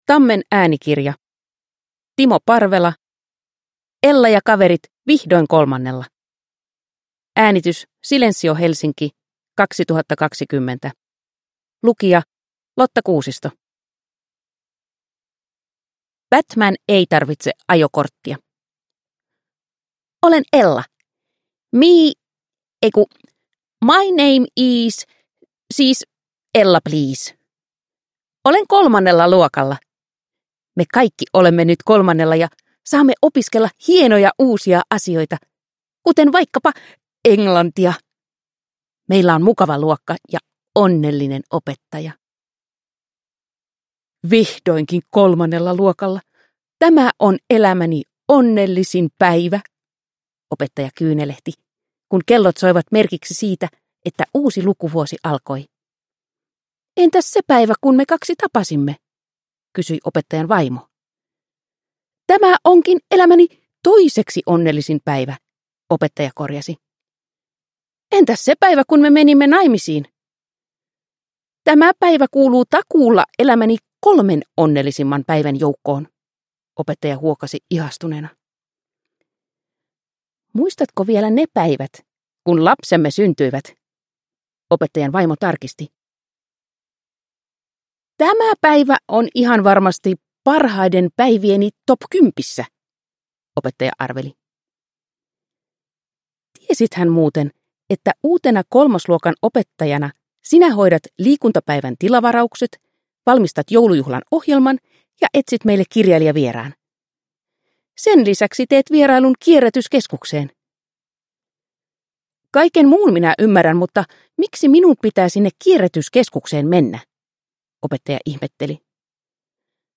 Ella ja kaverit vihdoin kolmannella – Ljudbok – Laddas ner